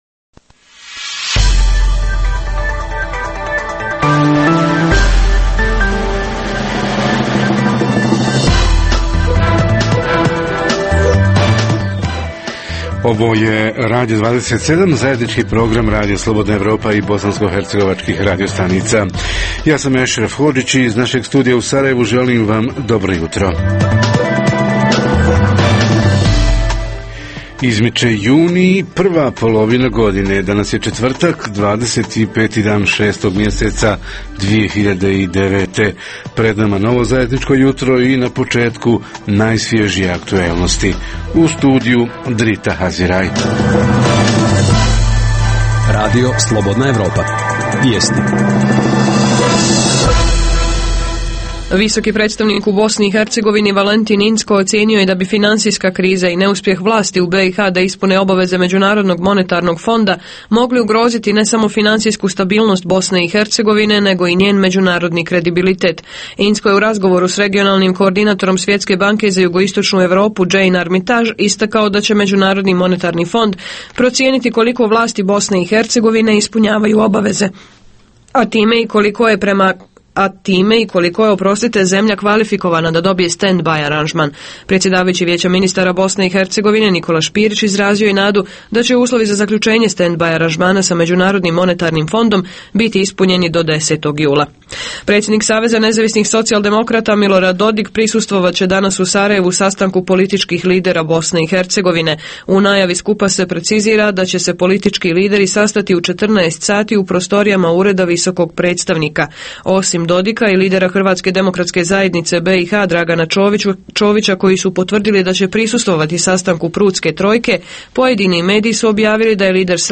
Upis u srednje škole – šta je ishod prvog upisnog roka? Reporteri iz cijele BiH javljaju o najaktuelnijim događajima u njihovim sredinama.
Redovni sadržaji jutarnjeg programa za BiH su i vijesti i muzika.